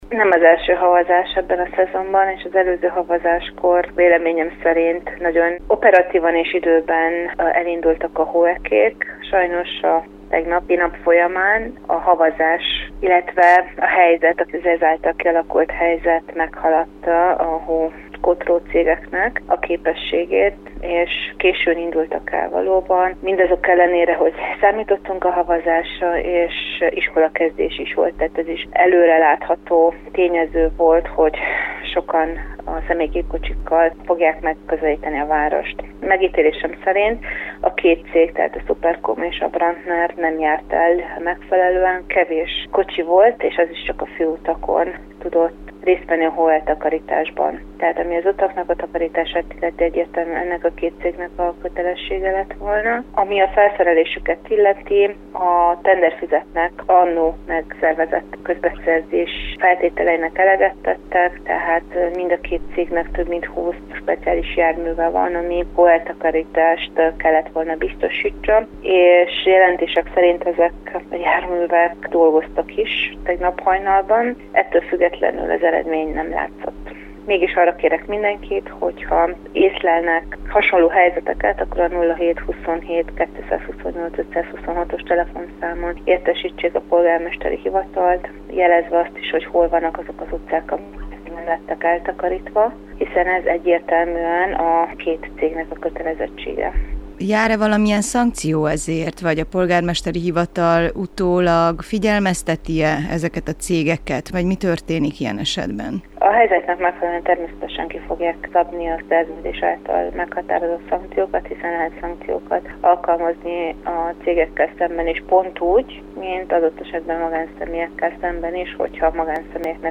Oláh Emese alpolgármestert kérdeztük.